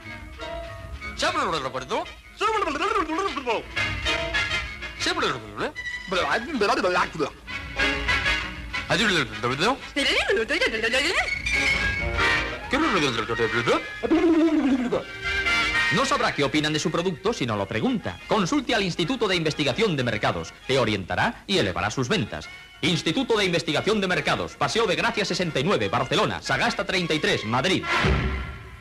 Publicitat de l'Instituto de Investigación de Mercados Gènere radiofònic Publicitat